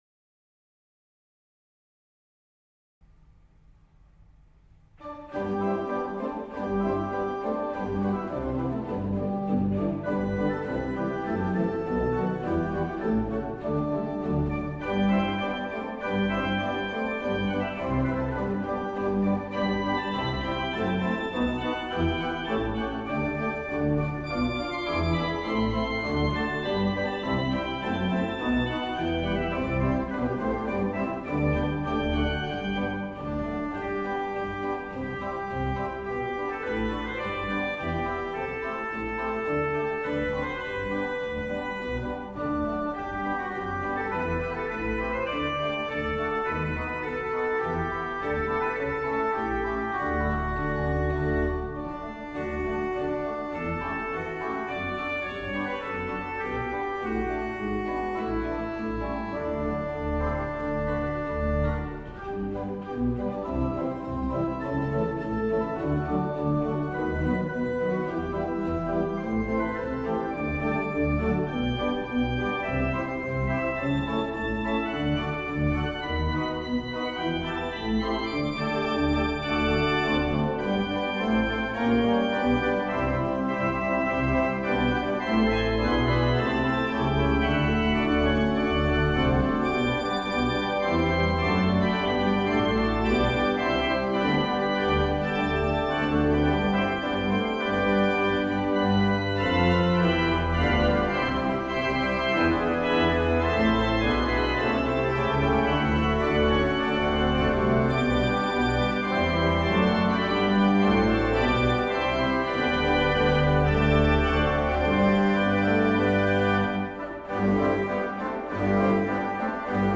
Er werd gekozen voor een echt Hollands orgel zoals dat met name in de eerste helft van de 19e eeuw werd gebouwd, met duidelijke herkenning van het werk van Bätz.
De vervanging van de kerkvloer betekende een aanzienlijke verbetering van de akoestiek, wat de klank van het orgel ten goede is gekomen.
De kerk heeft tegenwoordig een vrij goede akoestiek.
Bij de opbouw van het plenum (volle werk) valt dat ook al spoedig op. Het instrument bezit een volle, dragende klank van een mild karakter.
Een tintelende Mixtuur bekroont het geheel.